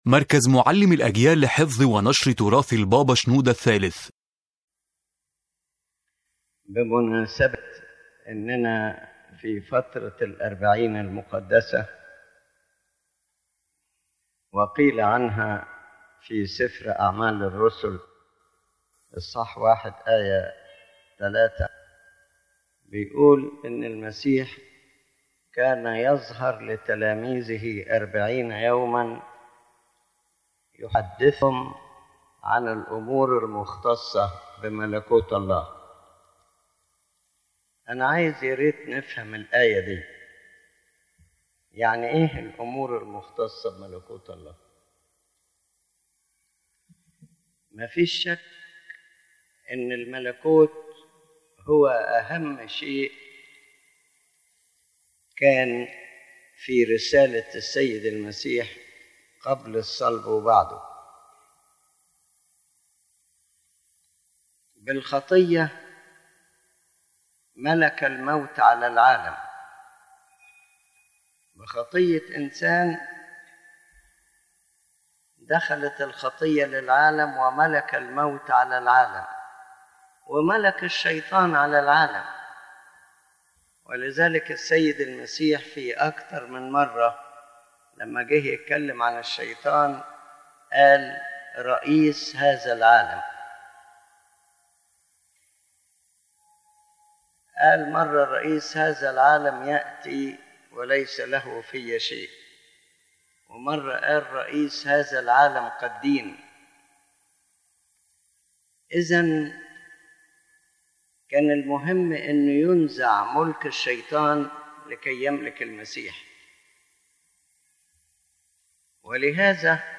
The General Message of the Lecture